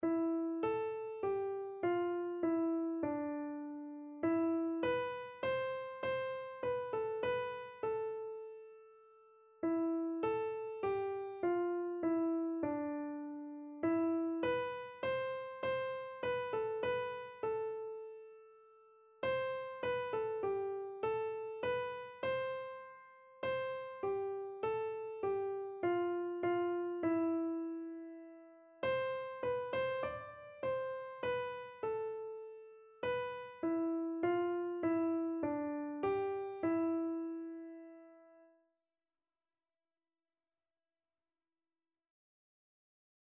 Keyboard version
Free Sheet music for Keyboard (Melody and Chords)
4/4 (View more 4/4 Music)
Keyboard  (View more Intermediate Keyboard Music)
Classical (View more Classical Keyboard Music)